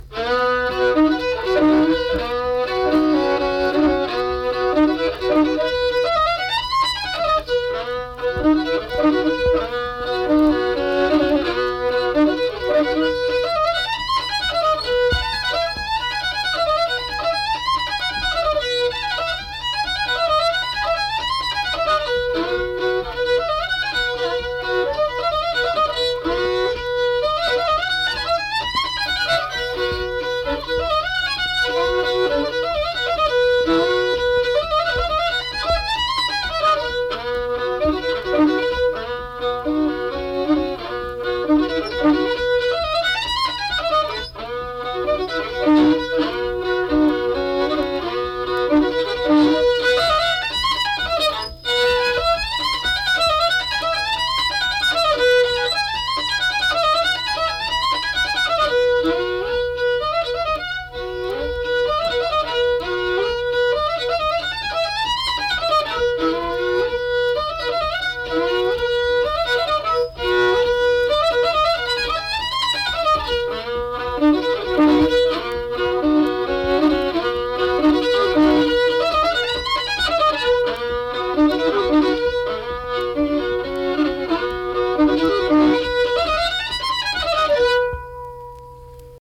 Cocklebur - West Virginia Folk Music | WVU Libraries
Unaccompanied fiddle music and accompanied (guitar) vocal music performance
Instrumental Music
Fiddle